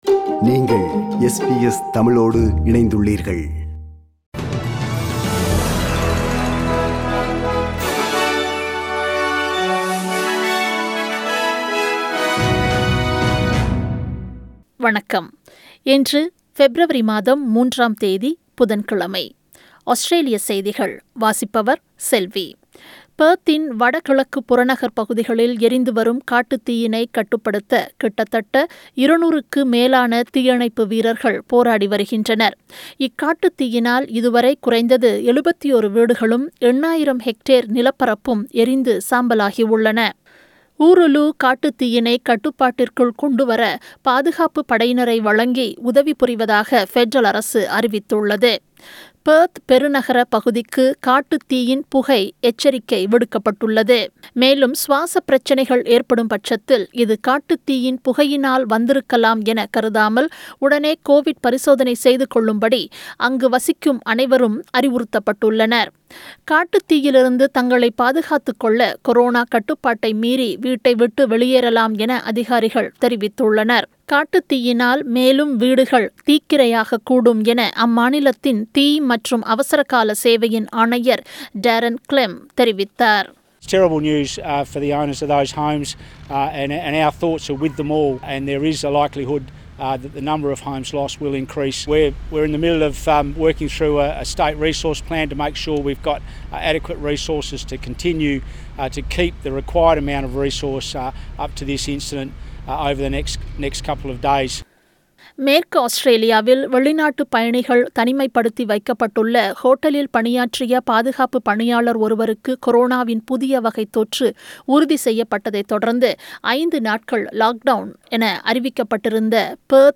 Australian news bulletin for Wednesday 03 February 2021.